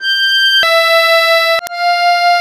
MUSETTESW.21.wav